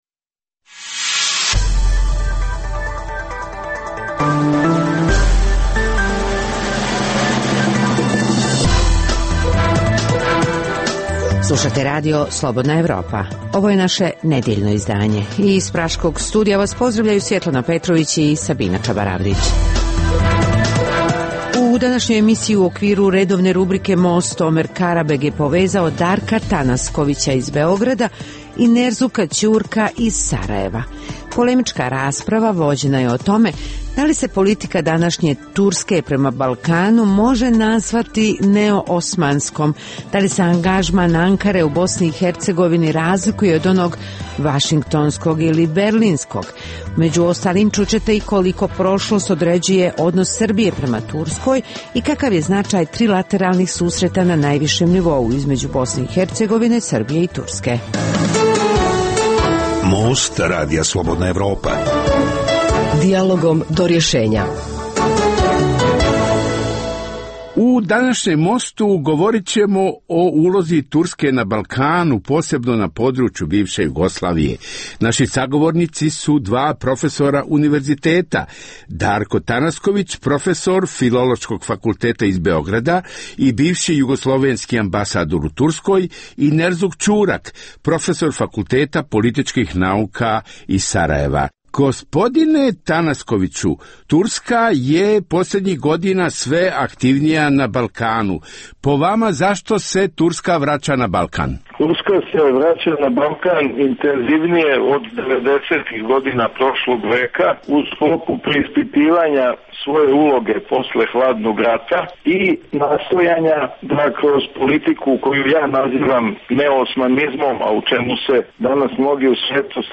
U najnovijem Mostu vođena je polemika o ulozi Turske na Balkanu.